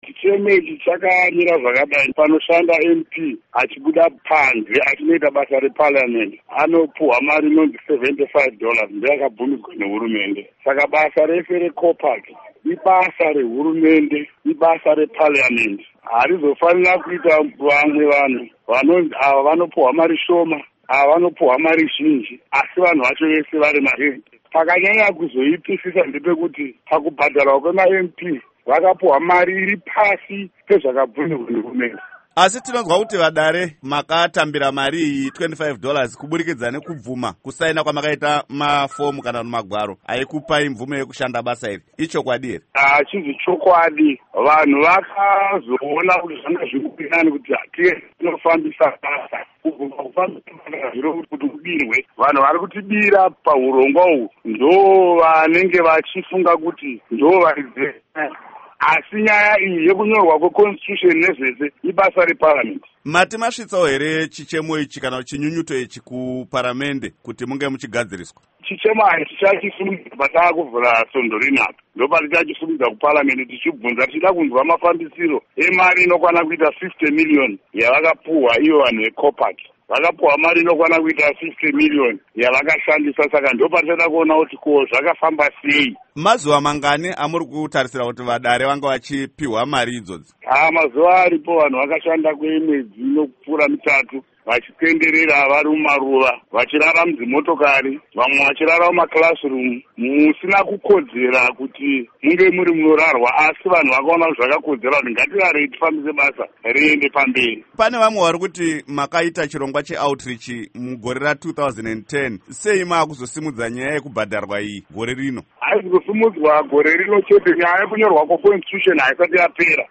Hurukuro naVaSimbaneuta Mudarikwa